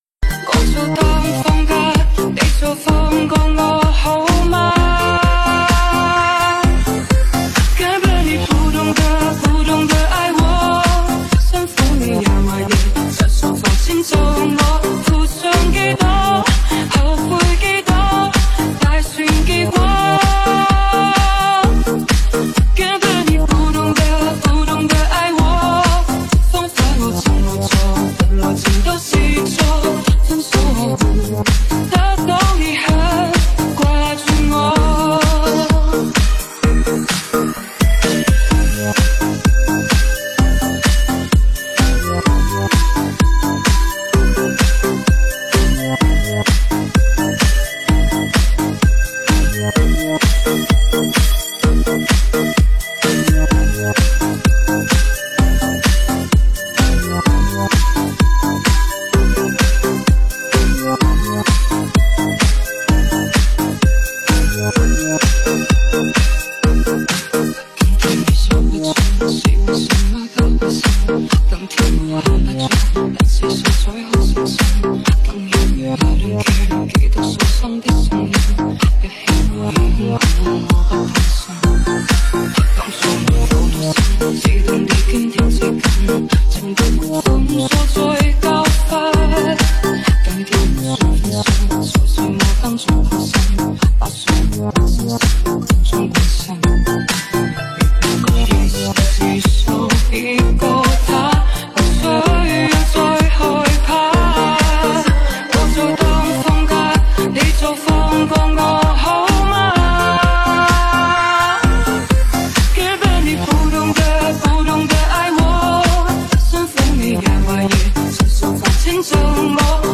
本铃声大小为617.3KB，总时长140秒，属于DJ分类。